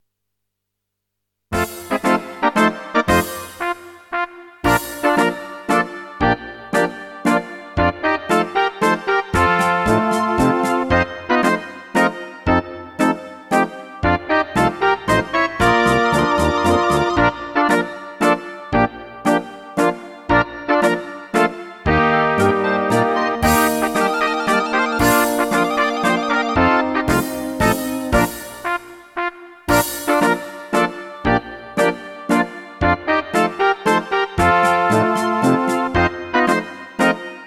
Rubrika: Národní, lidové, dechovka
- mazurka